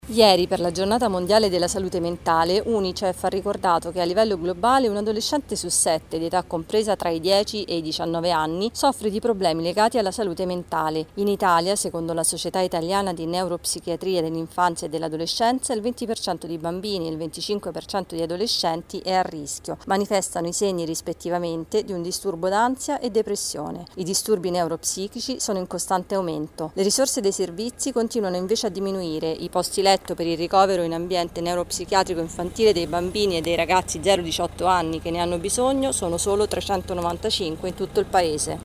Ieri per la Giornata mondiale della salute mentale i dati di Unicef e Società italiana neuropsichiatri dell’infanzia e dell’adolescenza. Il servizio